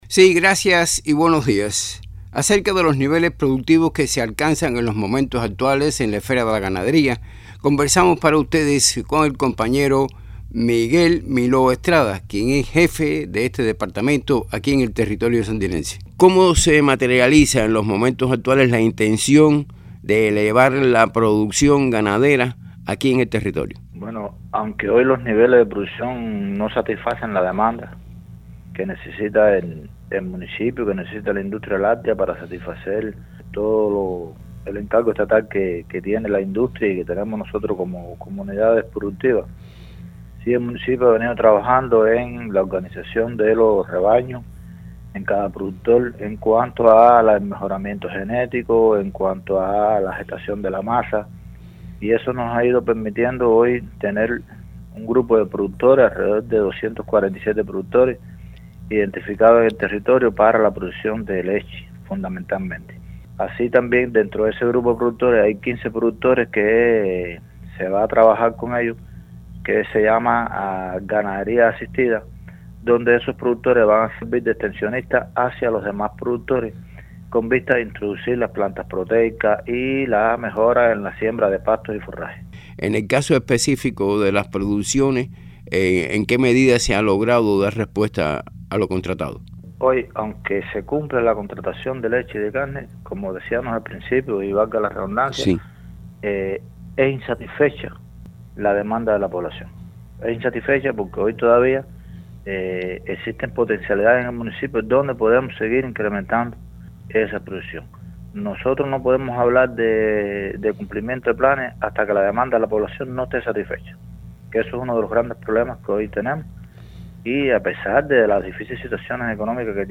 En torno a la situación actual de la misma conversó el periodista